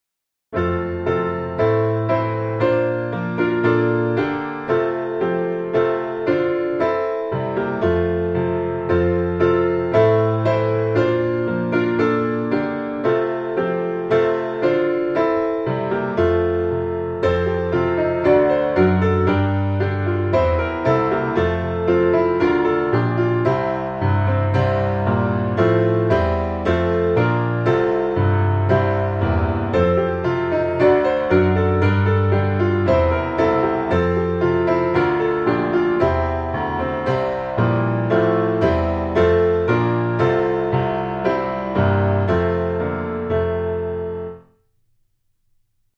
F Majeur